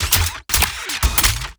GUNMech_Reload_12_SFRMS_SCIWPNS.wav